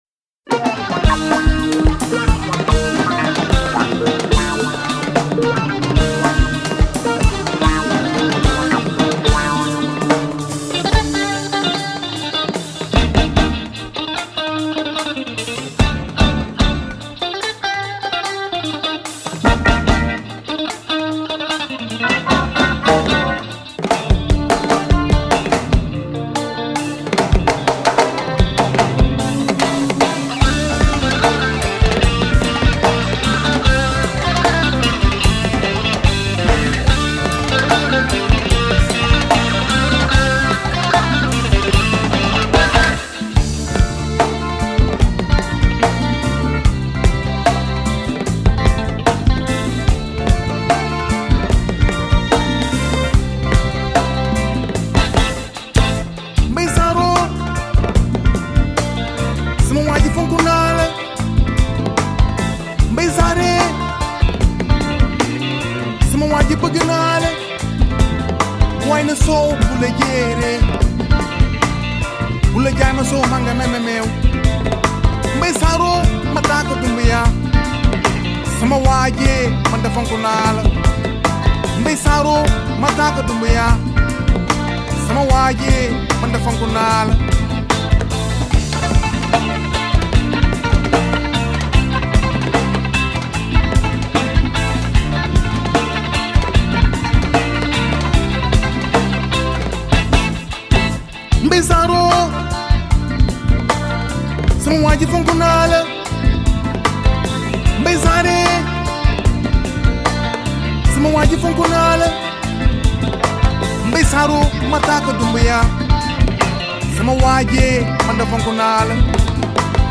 sabar percussion and lead vocals
drum kit
balafon